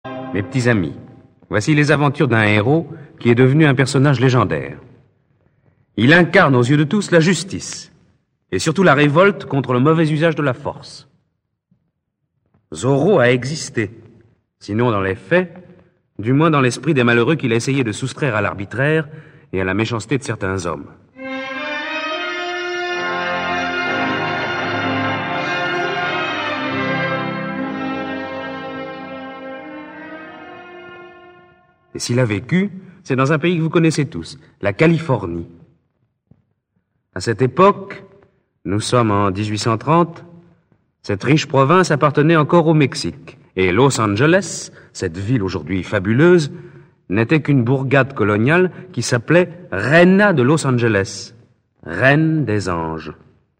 Vêtu de noir et masqué, Don Diego combat l'autorité sous le nom de 'Zorro' pour défendre le peuple contre le tyran. Zorro : Daniel Gélin
Enregistrement original de 1959